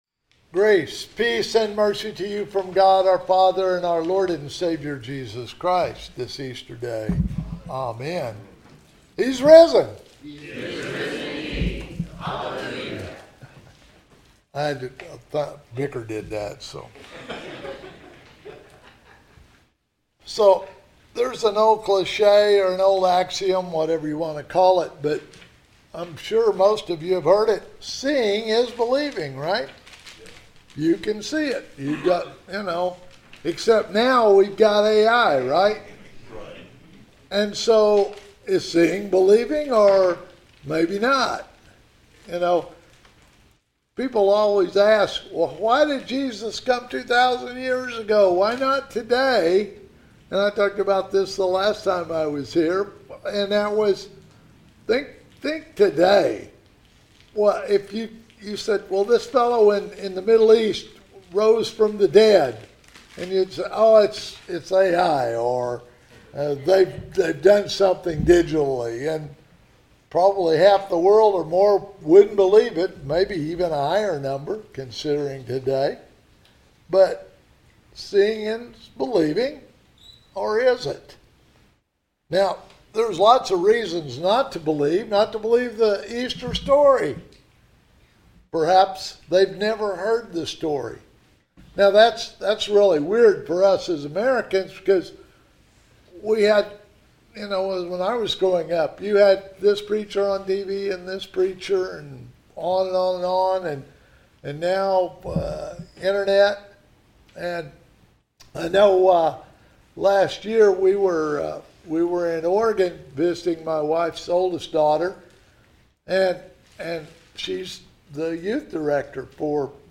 This weeks Sermon Audio